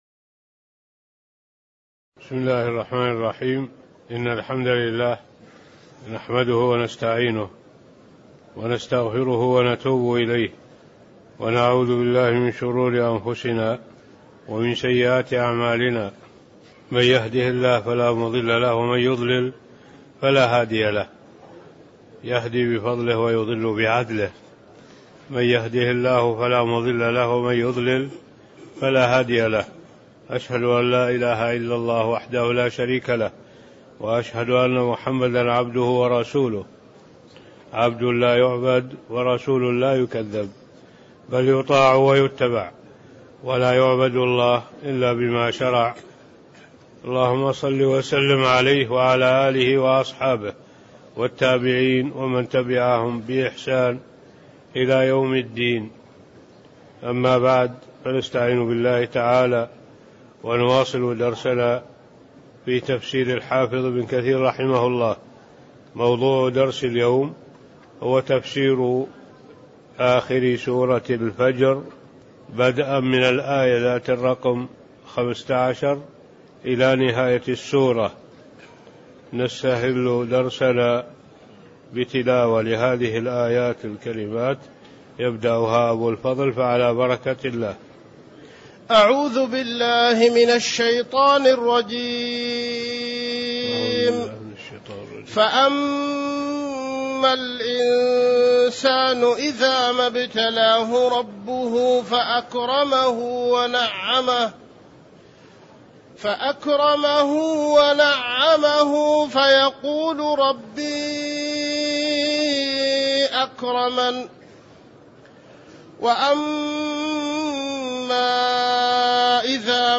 المكان: المسجد النبوي الشيخ: معالي الشيخ الدكتور صالح بن عبد الله العبود معالي الشيخ الدكتور صالح بن عبد الله العبود من أية 15-نهاية السورة (1175)